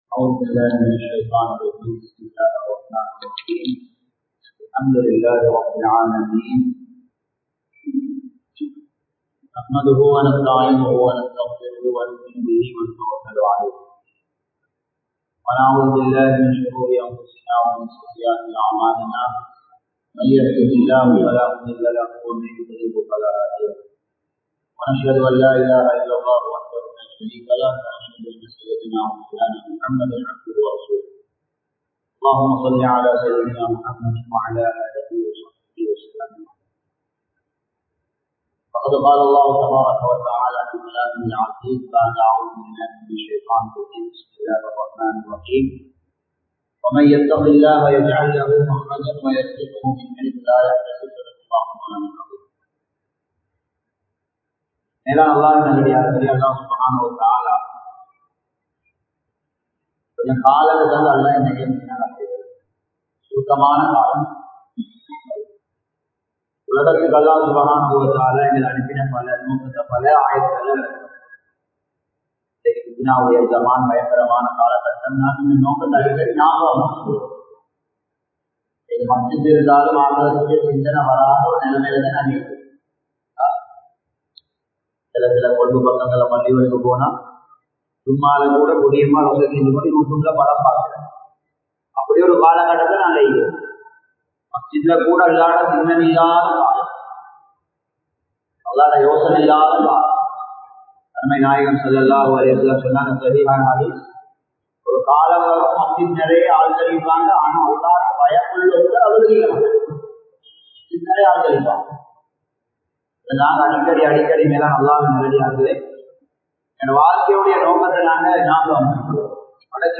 வாழ்க்கையில் உலகமோகம் | Audio Bayans | All Ceylon Muslim Youth Community | Addalaichenai
Beruwela, Maggonna, Grand Jumua Masjidh 2022-10-06 Tamil Download